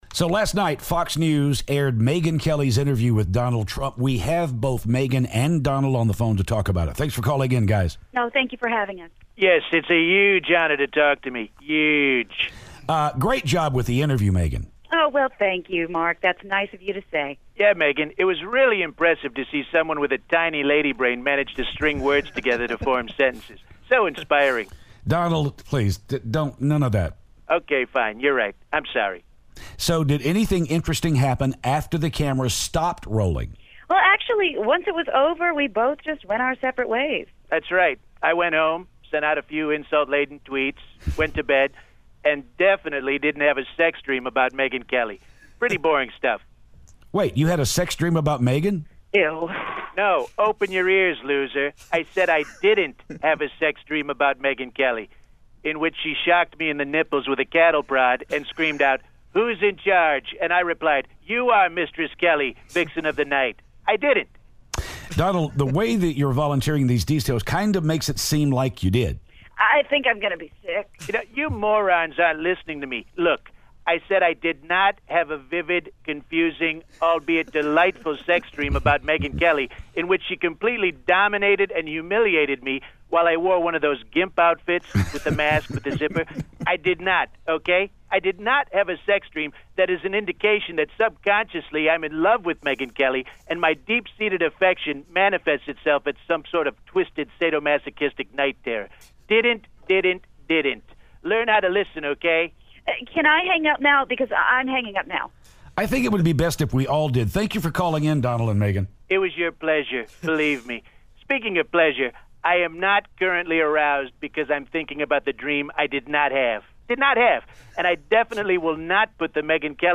Megyn Kelly, Donald Trump Phoner
Megyn Kelly and Donald Trump call to talk about the big interview last night.